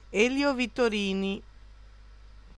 Elio Vittorini (Italian: [ˈɛːljo vittoˈriːni]
It-Elio_Vittorini.ogg.mp3